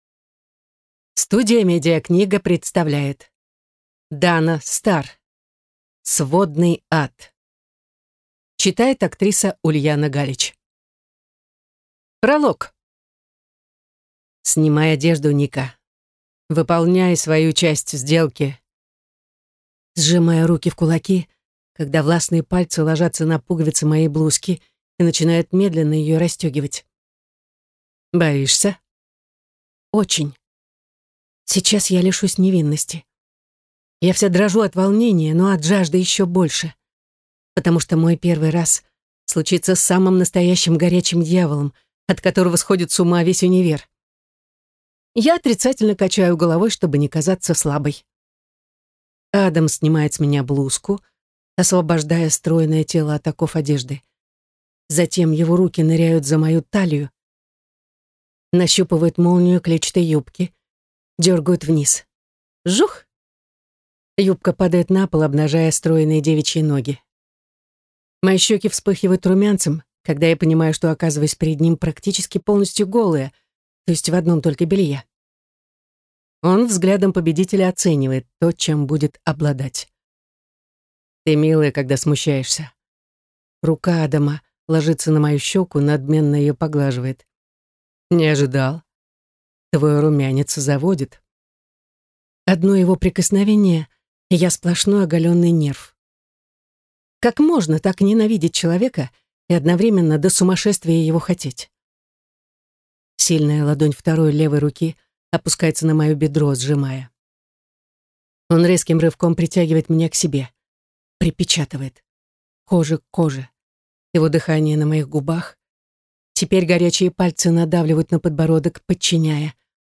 Аудиокнига Сводный ад | Библиотека аудиокниг